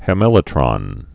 (hĕ-mĕlĭ-trŏn)